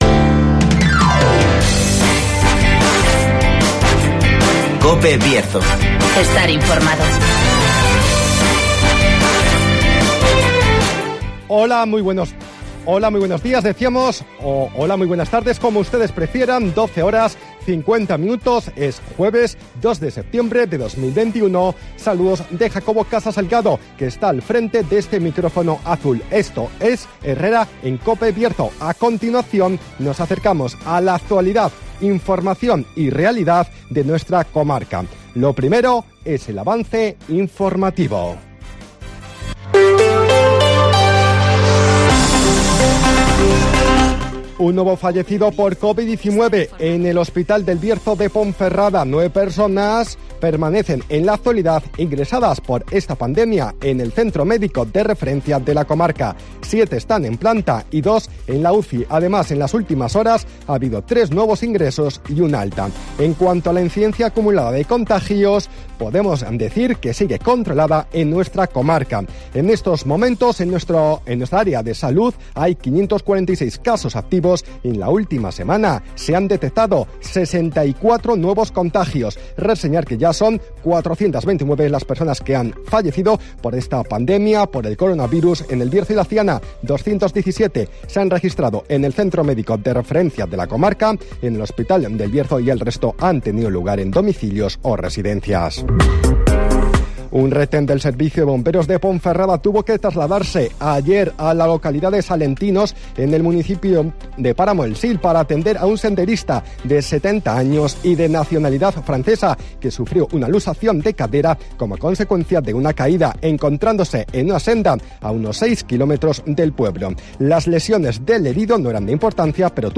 AUDIO: Avance informativo, El Tiempo y Agenda.